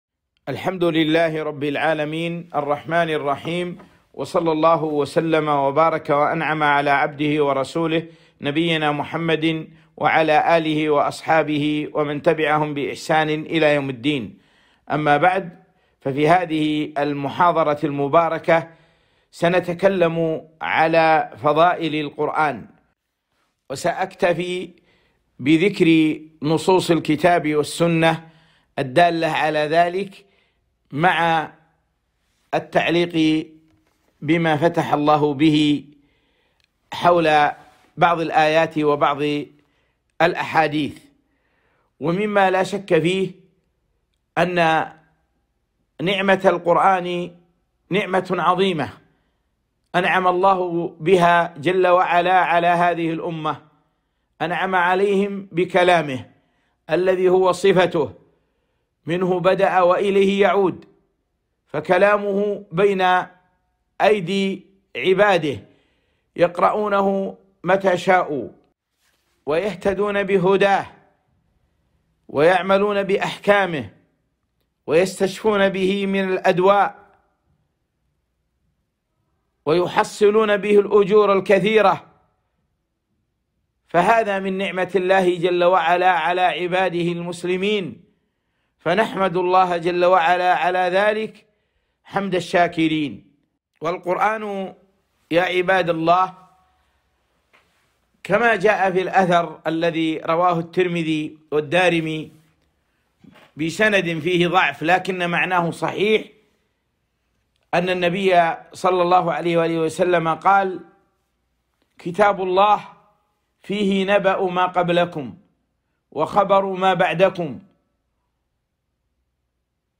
محاضرة - فضائل القرآن الكريم